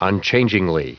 Prononciation du mot unchangingly en anglais (fichier audio)
Prononciation du mot : unchangingly
unchangingly.wav